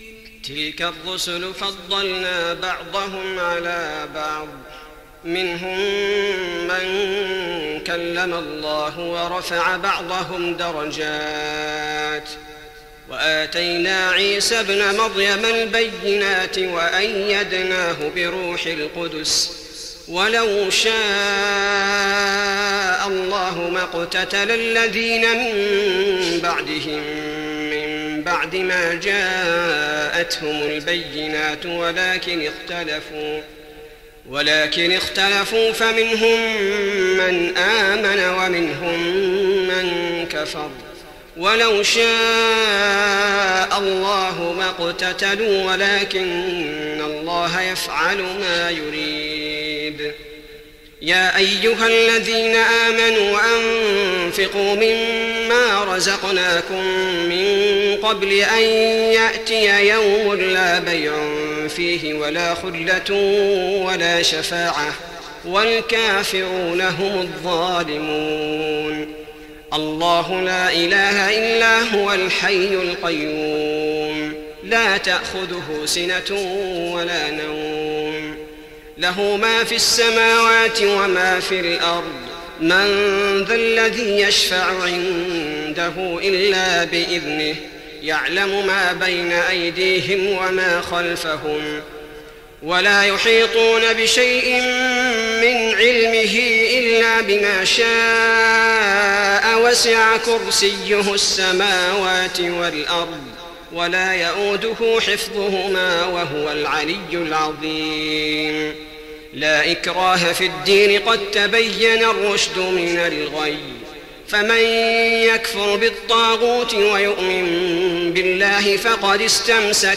تهجد رمضان 1415هـ من سورة البقرة (253-286) Tahajjud night Ramadan 1415H from Surah Al-Baqara > تراويح الحرم النبوي عام 1415 🕌 > التراويح - تلاوات الحرمين